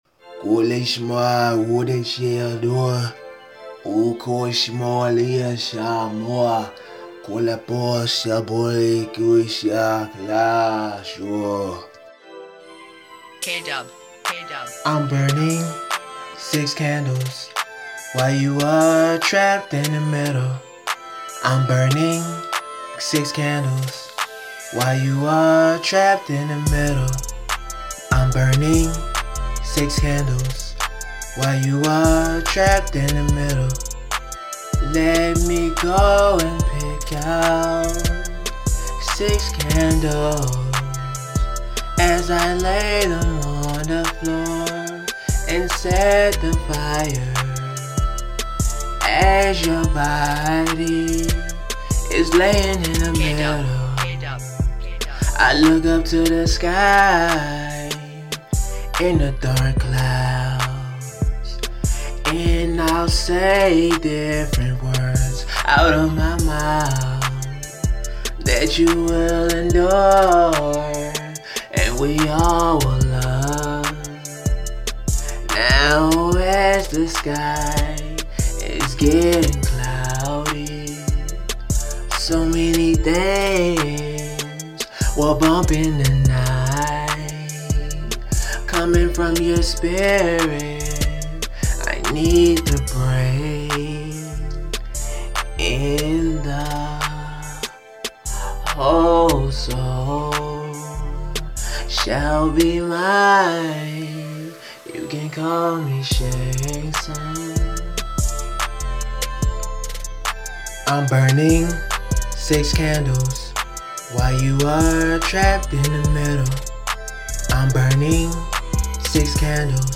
RnB
Haunting